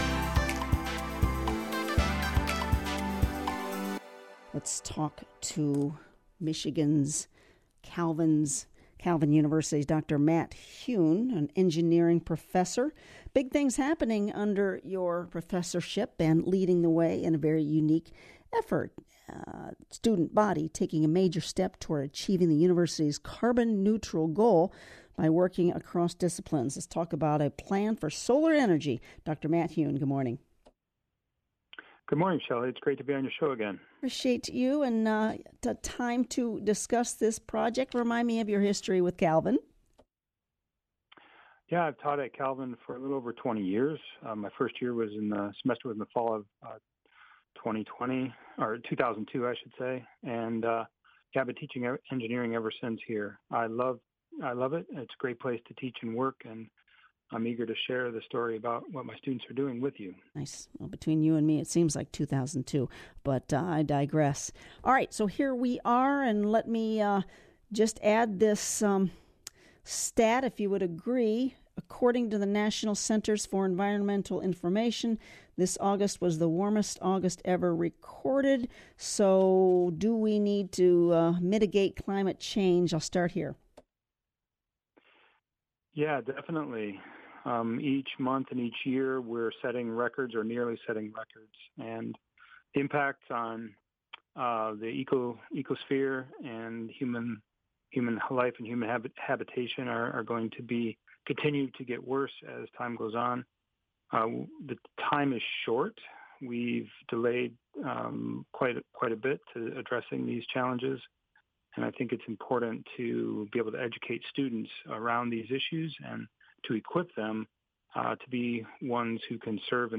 Calvin Solar Farm interview